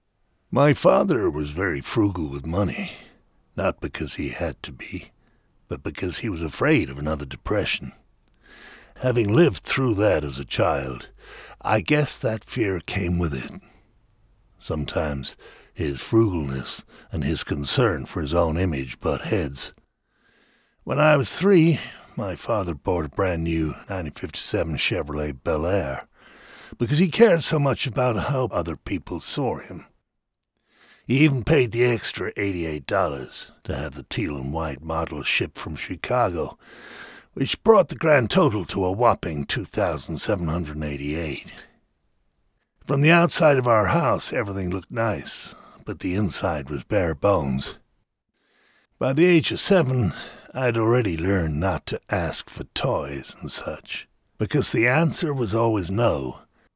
Adult male voice, warm and trustworthy, with clear diction and strong on-mic presence. Natural, smooth timbre capable of conveying credibility, closeness, and authority depending on the project's tone.